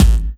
Kik Afrofour.wav